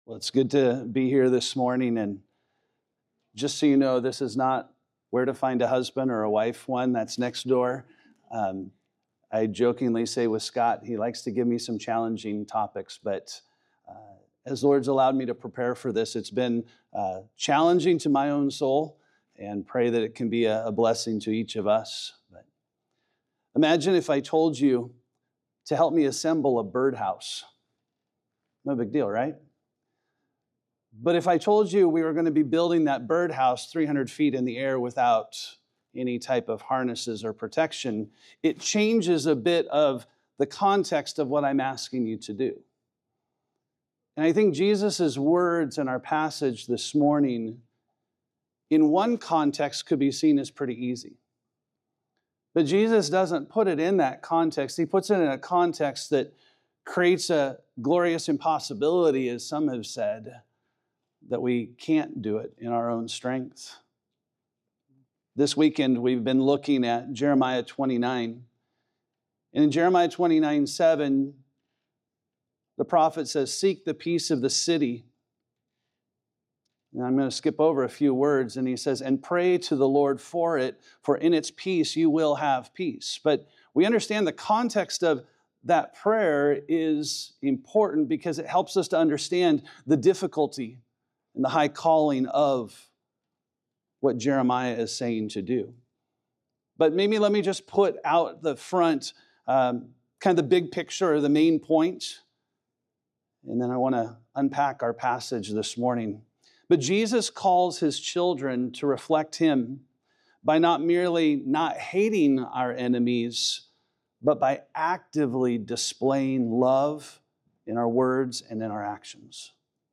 Generate Transcription kids coloring page In the Sermon on the Mount, Jesus presents the profound challenge of the moral law, revealing our need for God’s grace and strength.